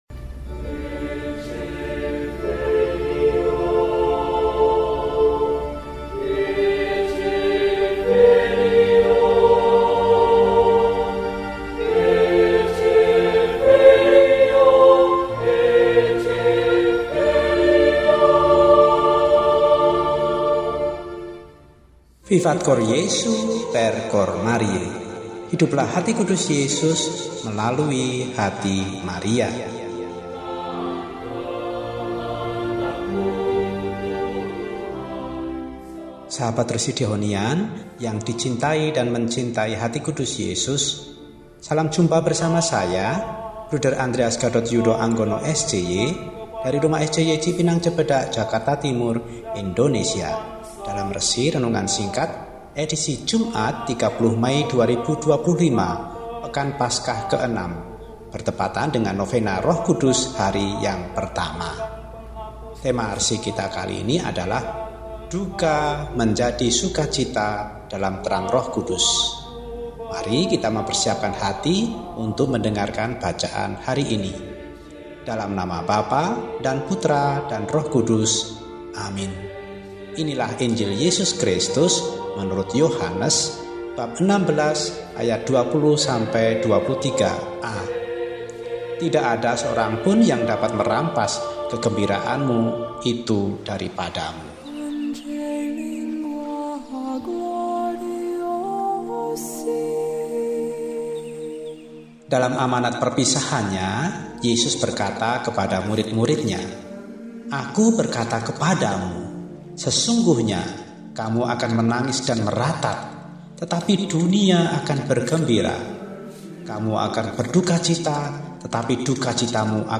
Jumat, 30 Mei 2025 – Hari Biasa Pekan VI Paskah (Novena Roh Kudus Hari Pertama) – RESI (Renungan Singkat) DEHONIAN